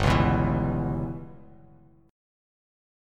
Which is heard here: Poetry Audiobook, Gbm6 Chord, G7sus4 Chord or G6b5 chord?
G6b5 chord